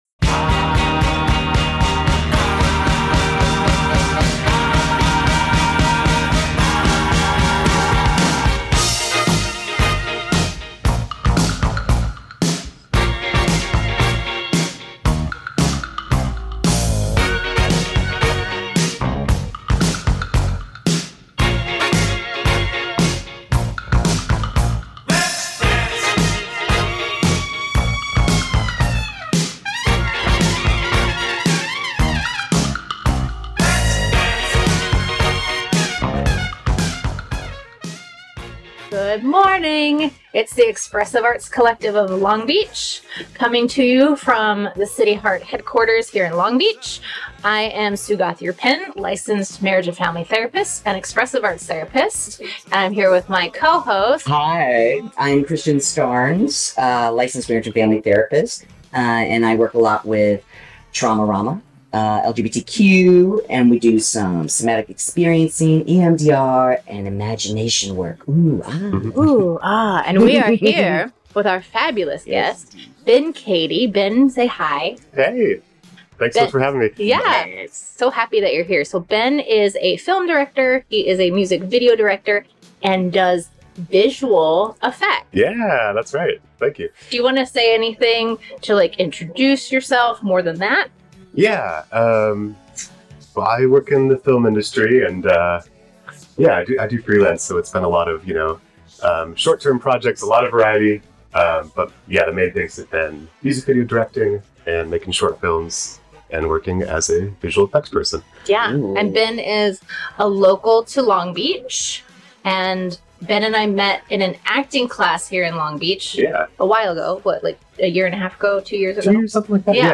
Hosted By Expressive Arts Collective of Long Beach
This episode aired live on CityHeART Radio on Sept. 13 at 10:30am.